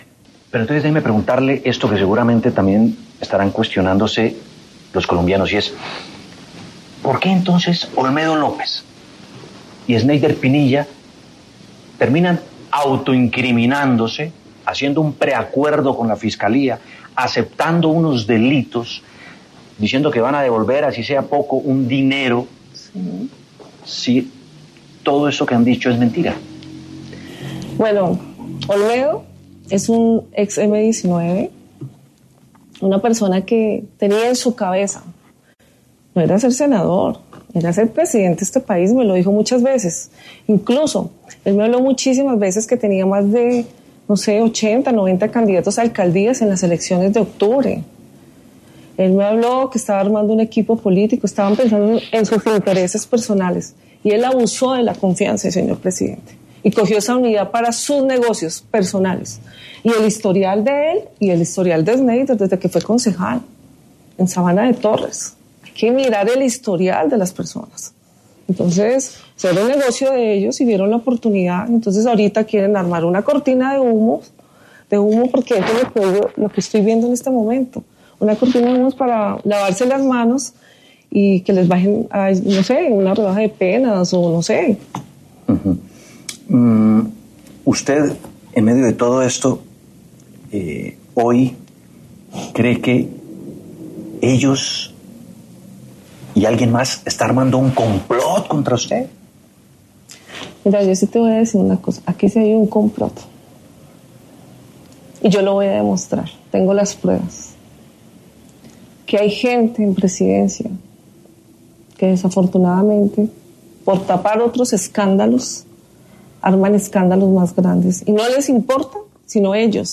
En entrevista exclusiva con La W, Sandra Ortiz, exconsejera para las Regiones, dio detalles del entramado de corrupción de la Unidad Nacional de Gestión del Riesgo y aseguró que hay gente en la Presidencia de la República que crea escándalos para tapar otros aún mayores.